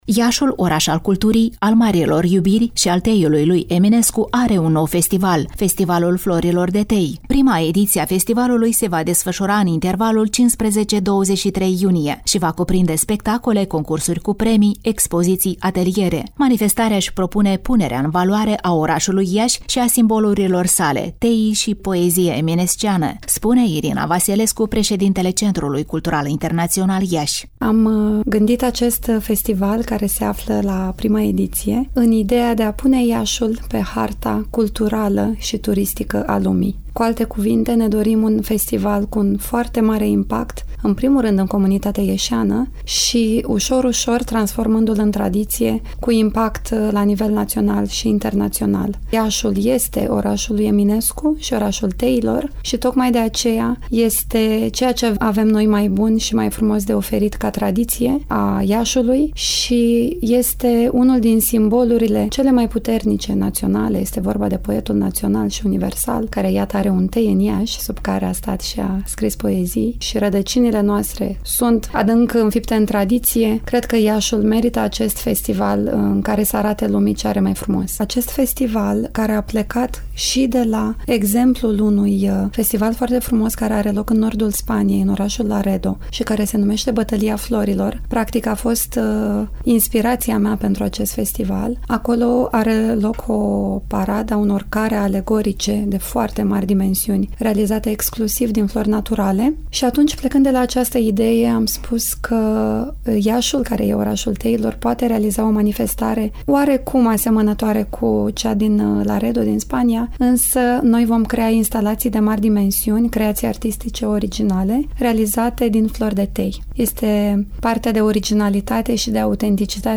Prima pagină » Rubrici » Reportaj cultural » Festivalul Florilor de Tei